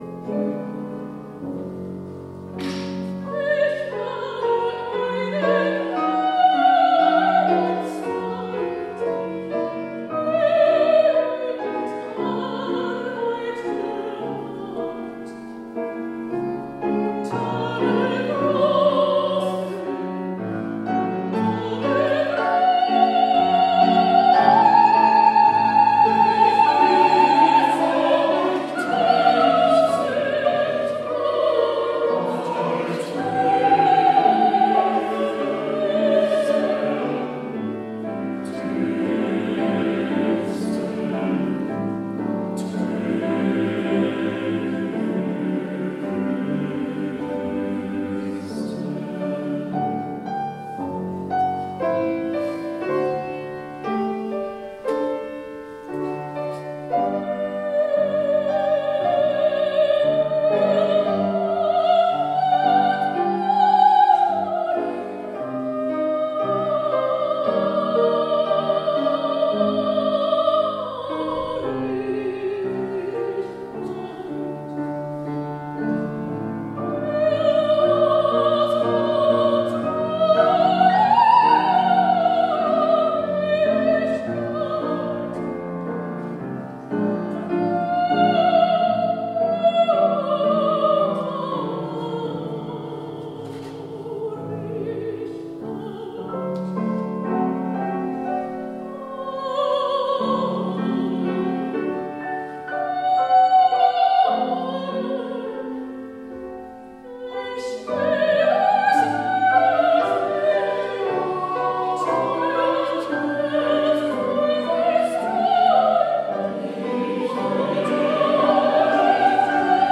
Oratorium